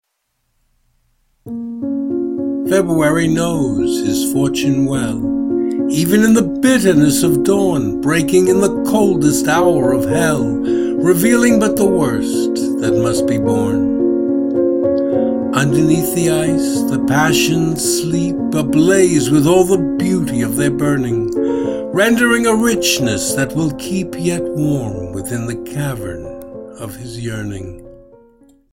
Video and Audio Music: